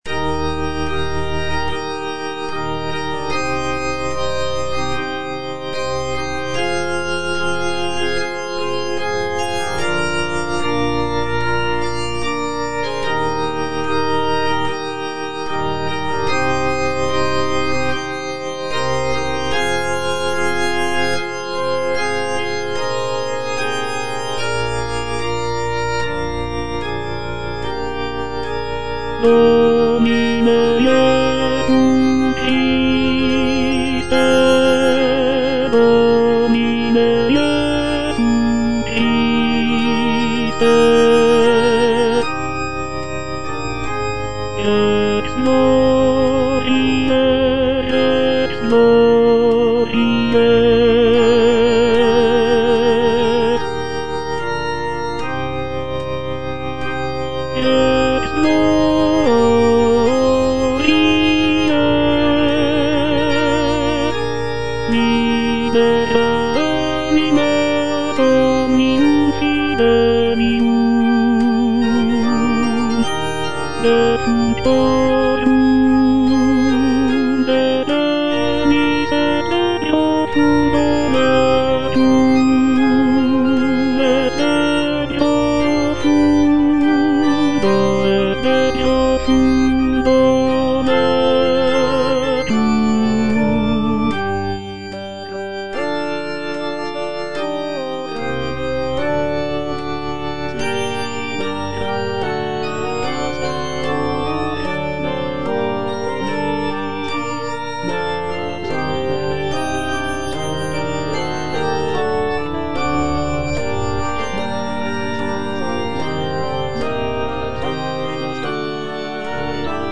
Tenor (Voice with metronome
is a sacred choral work rooted in his Christian faith.